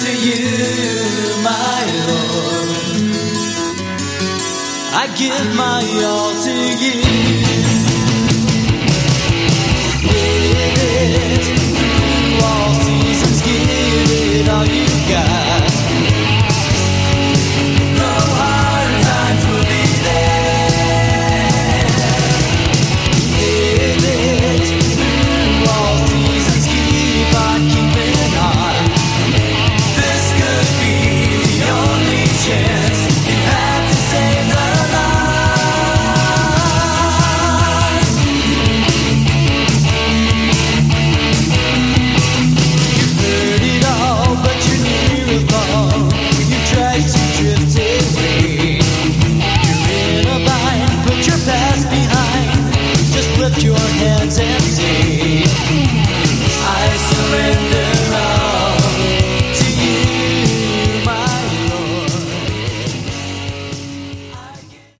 Category: Christian Hard Rock